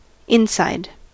Ääntäminen
IPA : /ˈɪnsaɪd/